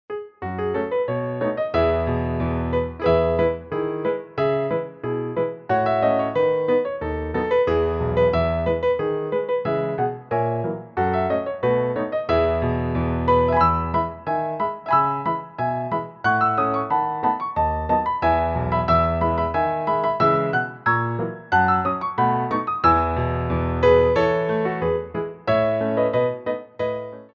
for Ballet Class
Jetés
4/4 (16x8)